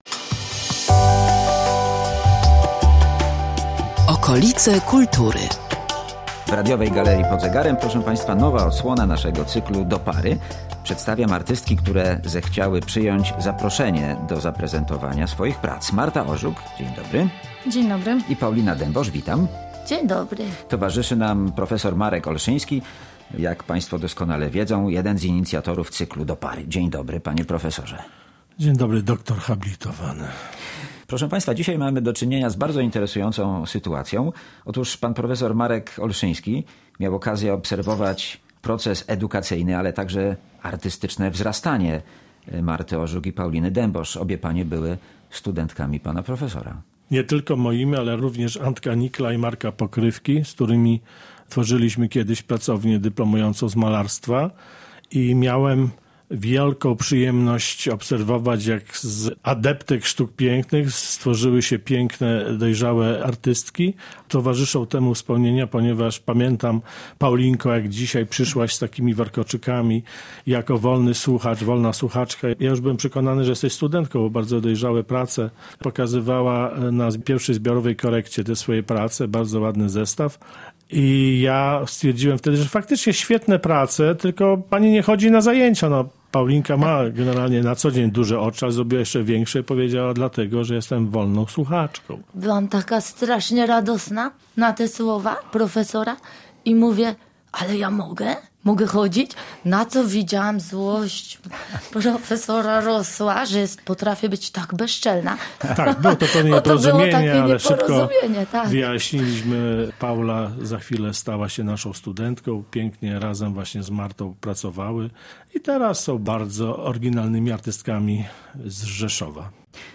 Z autorkami prac rozmawiają: